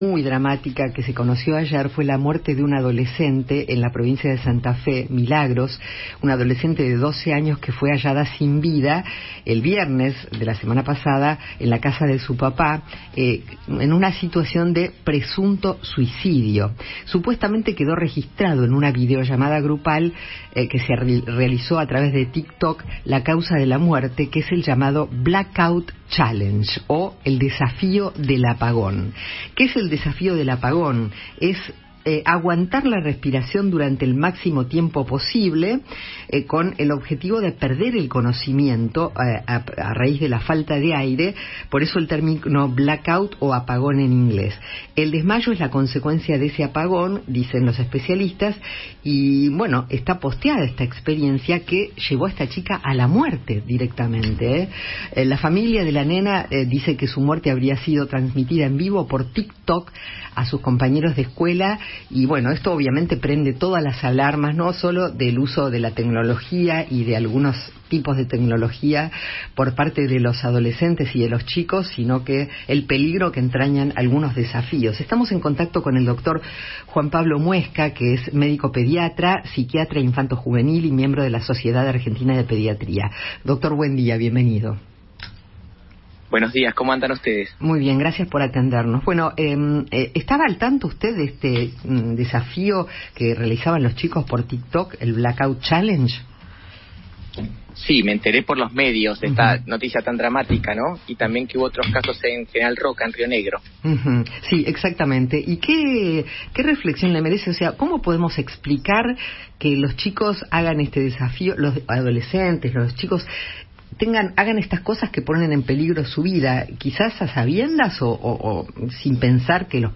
conversó en Alguien Tiene Que Decirlo sobre la adolescente de 12 años que fue hallada sin vida en su casa de Santa Fe, tras realizar un peligroso reto viral de Tik Tok.